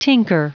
Prononciation du mot tinker en anglais (fichier audio)
Prononciation du mot : tinker